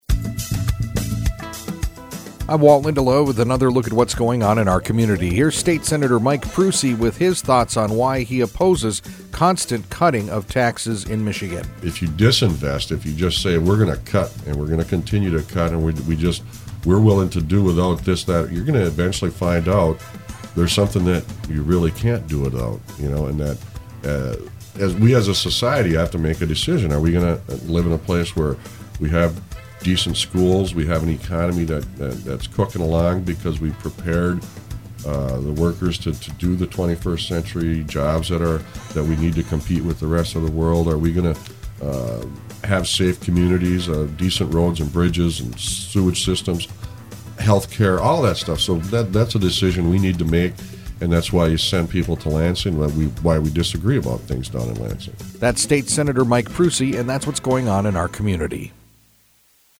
INTERVIEW: State Senator Mike Prusi